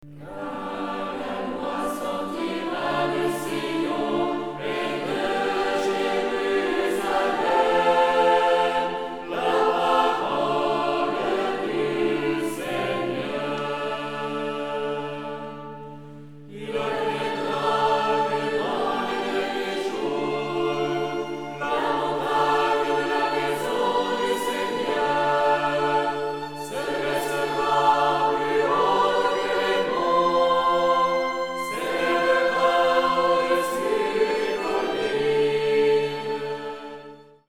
Deux offices des Laudes du temps ordinaire.